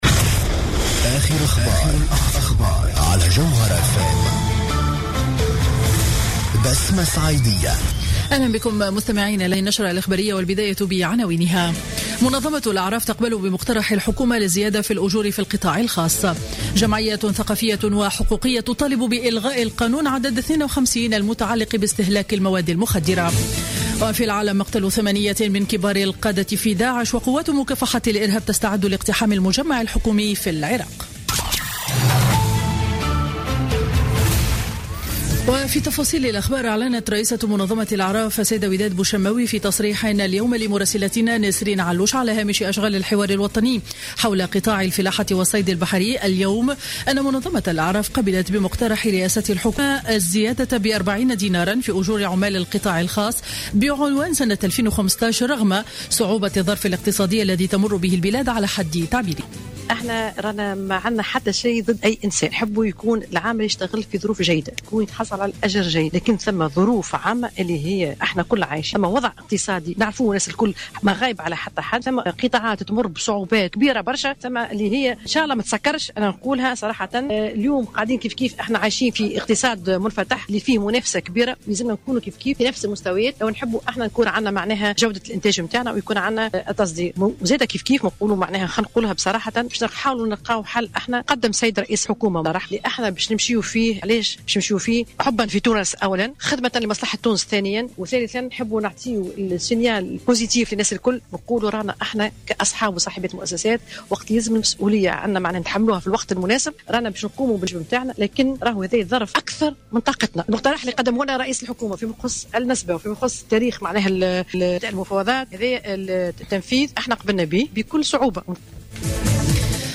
نشرة أخبار منتصف النهار ليوم الأربعاء 23 ديسمبر 2015